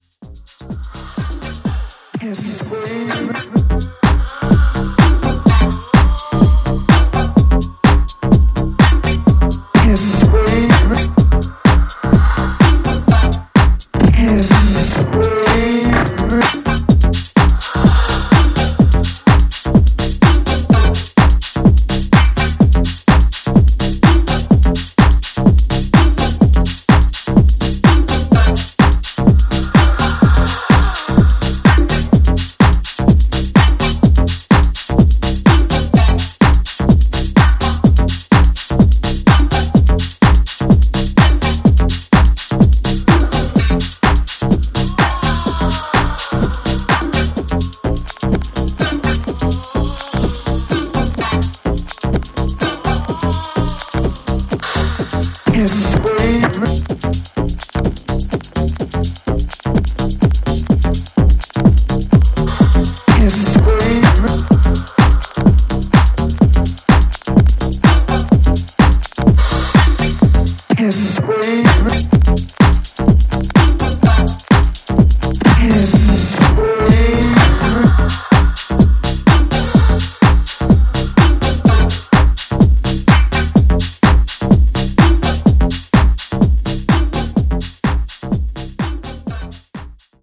ジャンル(スタイル) TECH HOUSE / DEEP HOUSE / MINIMAL